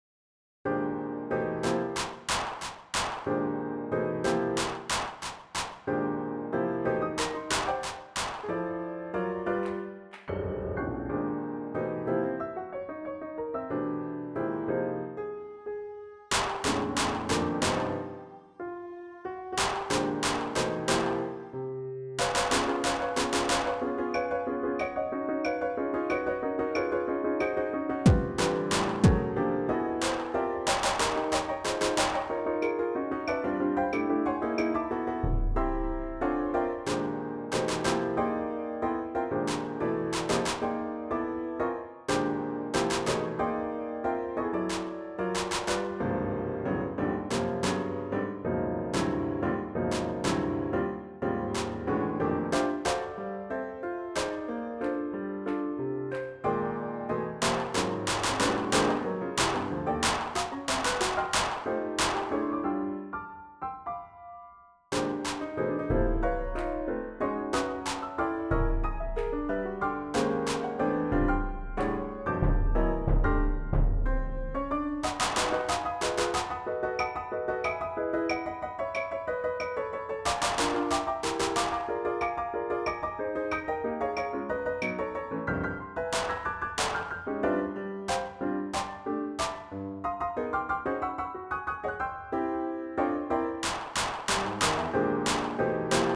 It’s hard to tell in this video, but what the students learned through discipline and fun was the following, presented in piano score and including stomping, clapping,etc..  The upper stemmed notes are the clapping pattern for one choir, and the lower stemmed notes the other–Cut Time IIa is purely didactic.
Cut Time’s Symmetry:  the short work is in C major.